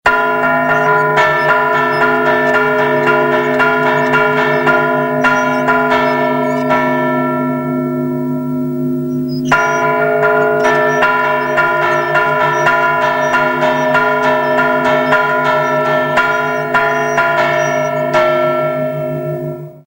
A continuació podeu escoltar uns quants "tocs" de campanes:
Dia normal a missa
misa diumenge.mp3